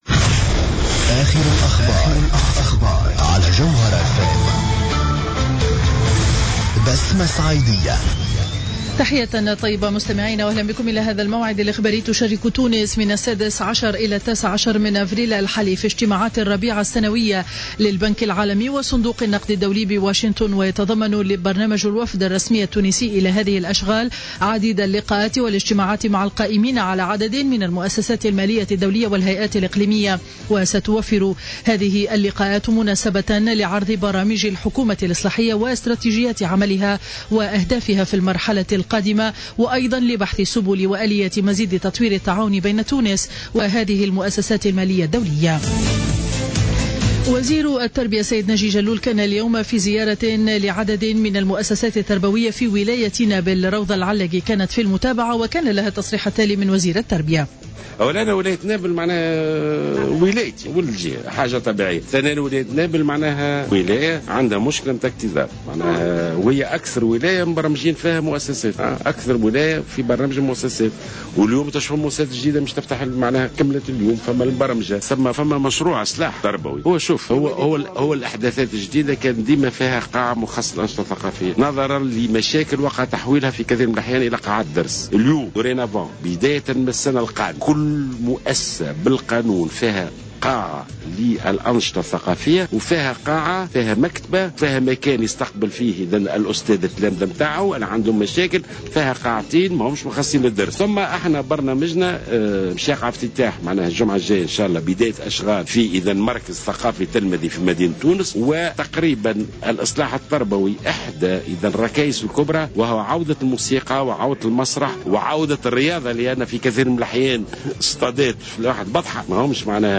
نشرة أخبار منتصف النهار ليوم الخميس 16 أفريل 2015